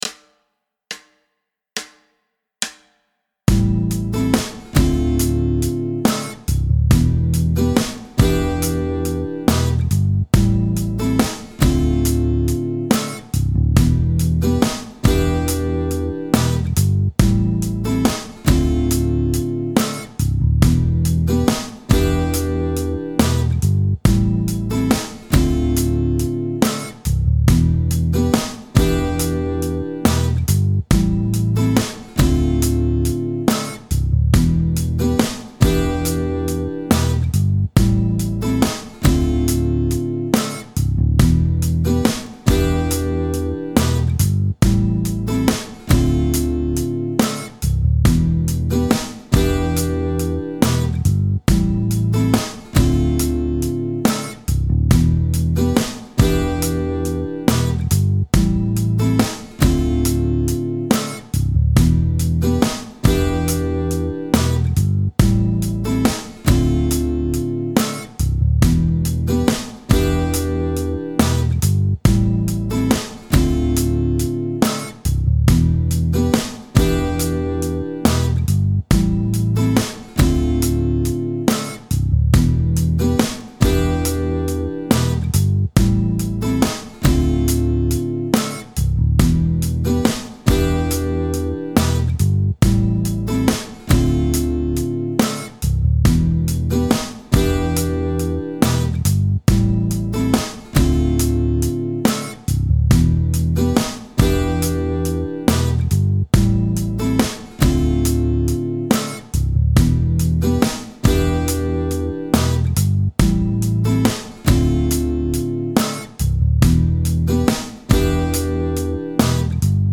Hier findest Du Jamtracks zur Begleitung beim Gitarre üben.